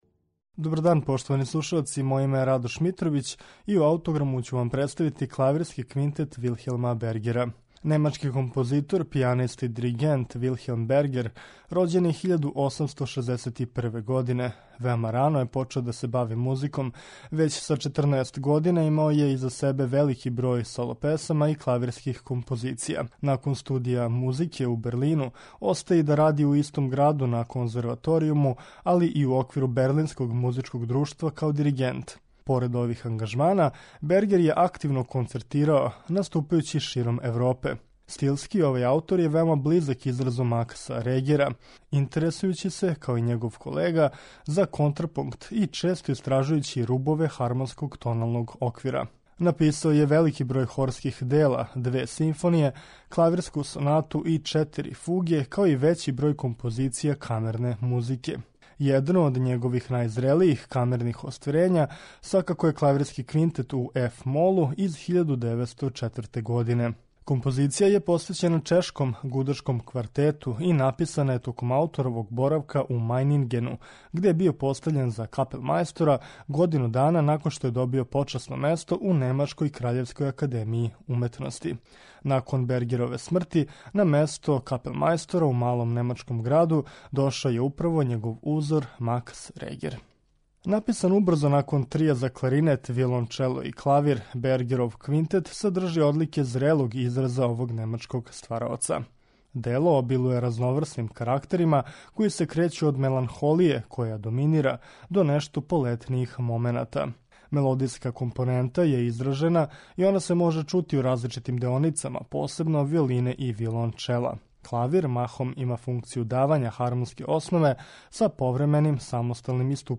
Представићемо Клавирски квинтет у еф-молу, Вилхелма Бергера